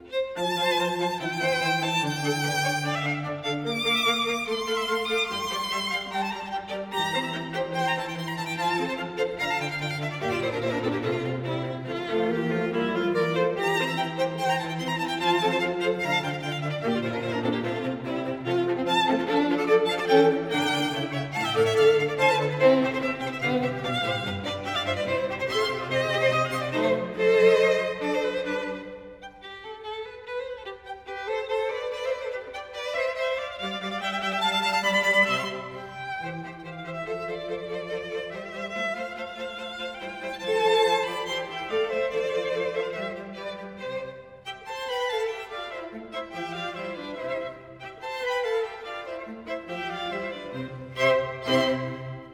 Струнный квартет НОНА. Живая музыка для ВАС!